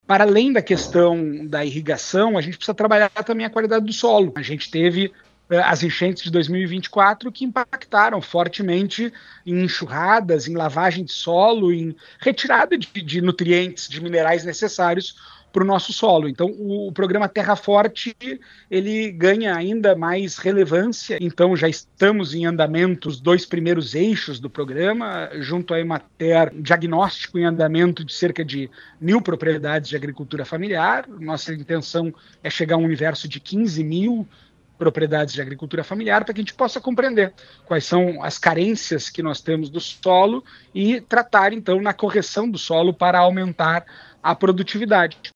Ele assumiu a pasta recentemente em substituição a Vilson Covati e ontem, as 6 horas e 30 minutos, concedeu entrevista no programa Progresso Rural da RPI.
Em relação à qualidade do solo, o novo secretário de Desenvolvimento Rural do Rio Grande do Sul cita o programa Operação Terra Forte, iniciado no final do ano passado, e que visa, justamente, melhorar as áreas produtivas. (Abaixo, áudio de Gustavo Paim)